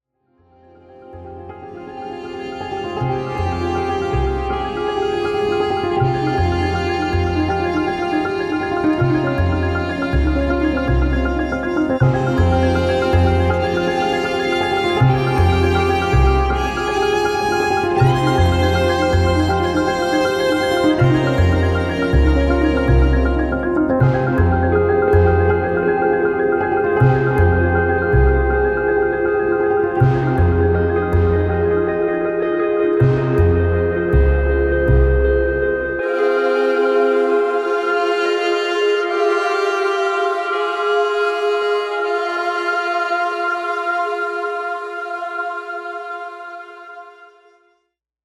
a hopeful but somber note, lots of piano, strings...